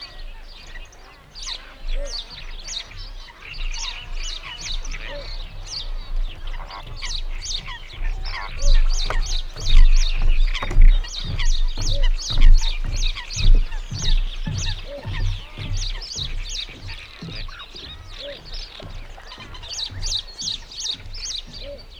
footsteps.wav